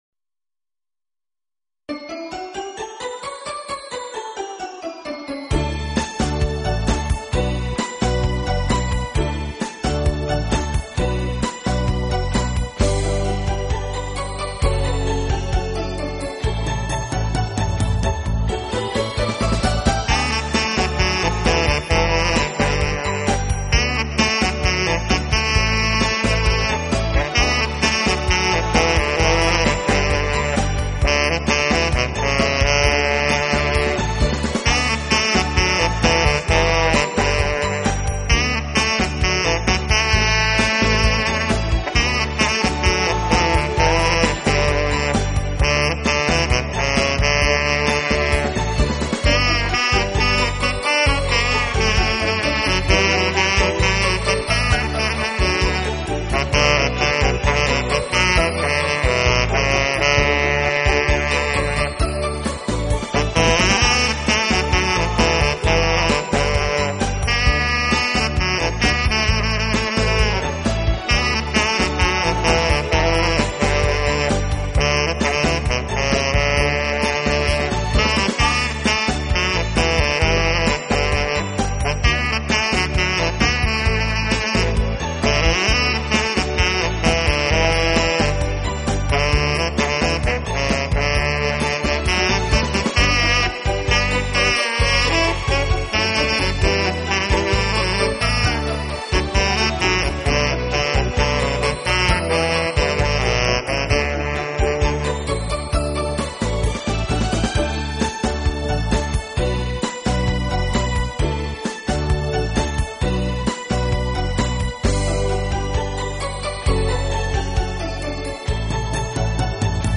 一杯咖啡伴随一首萨克斯音乐，让我们一起度过这最浪漫的时刻……